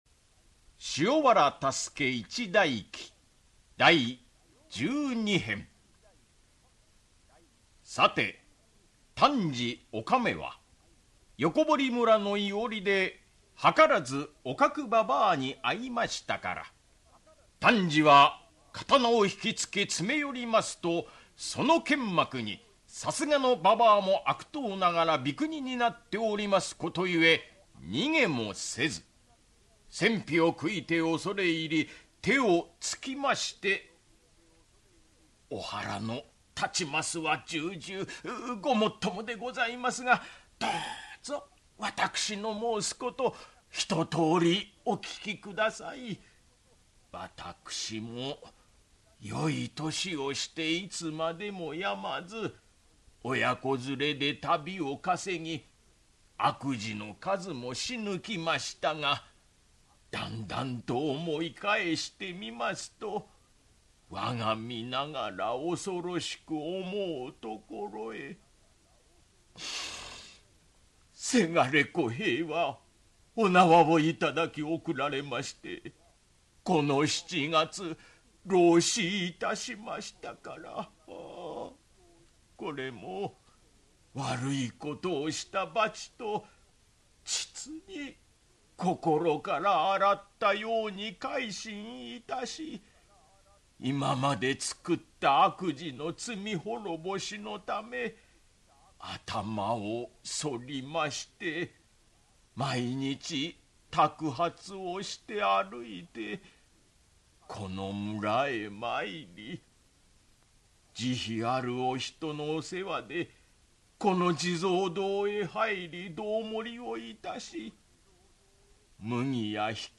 [オーディオブック] 塩原多助一代記-第十二・十三編-